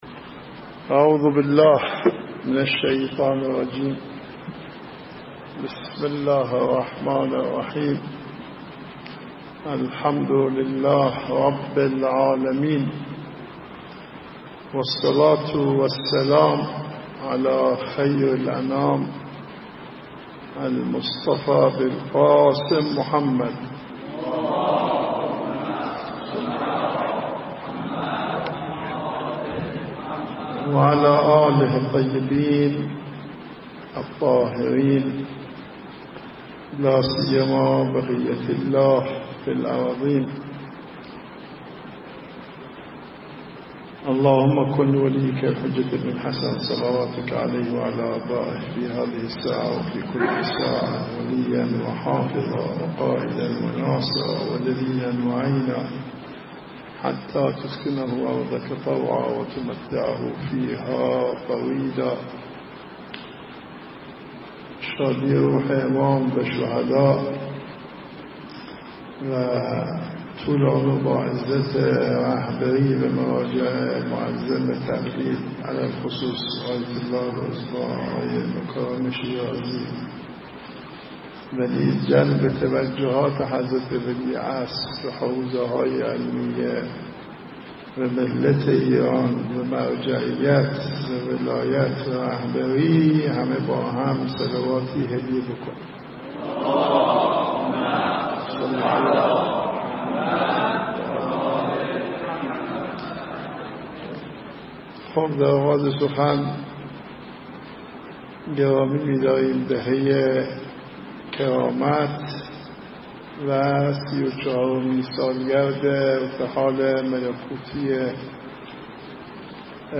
سخنرانی آیت الله کعبی حفظه الله در اختتامیه سومین جشنواره علمی
مراسم اختتامیه سومین جشنواره علمی مدرسه